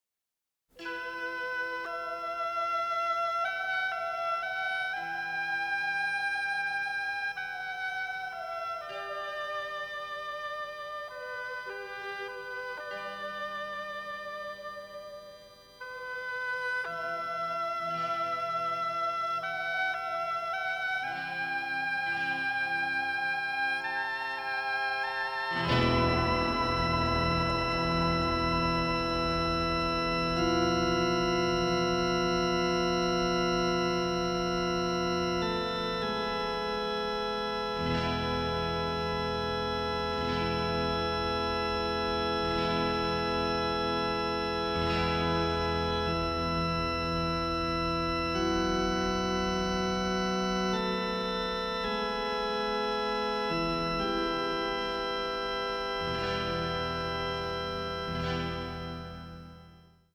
western score